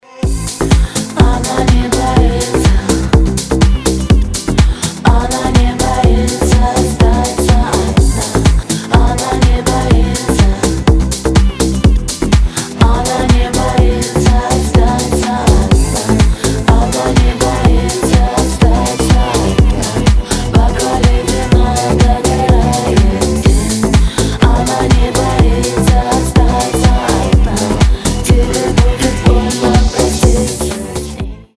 • Качество: 320, Stereo
поп
клубняк